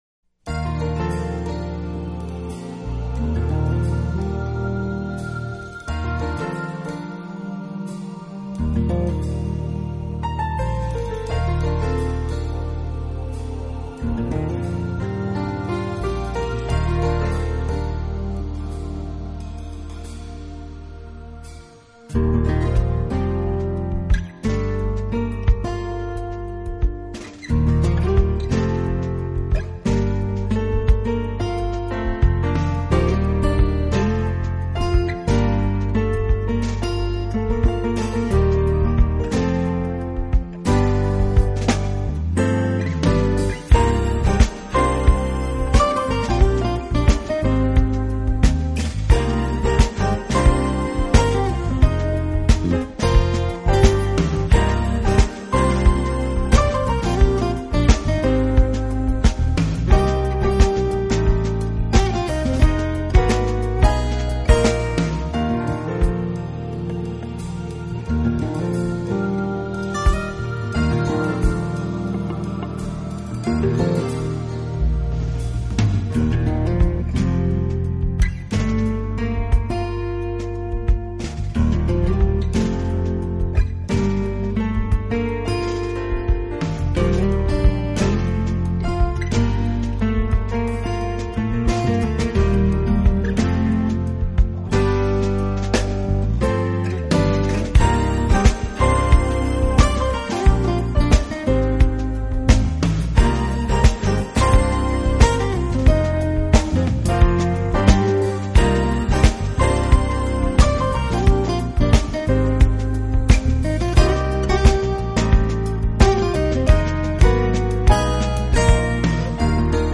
发生很大变化；平滑流畅的进行，温和的rock节奏衬托下优美的爵士情绪，柔和的人声，